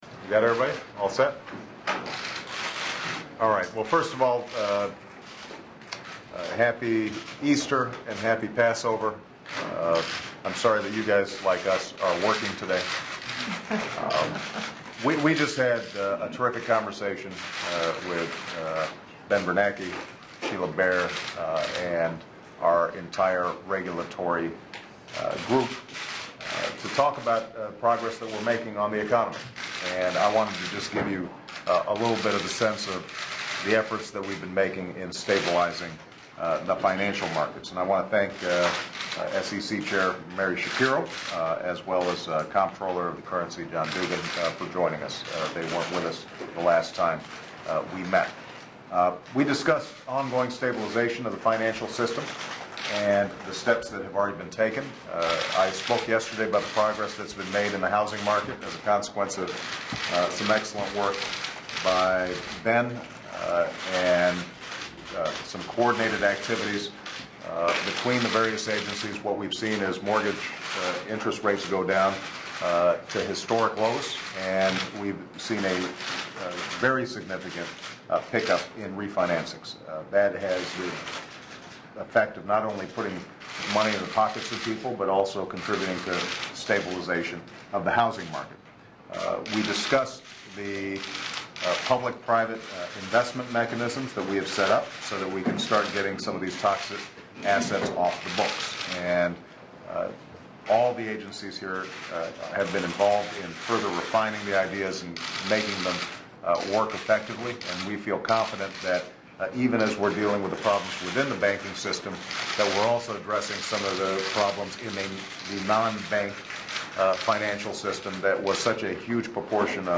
U.S. President Barack Obama speaks briefly to reporters before his State of the Economy press conference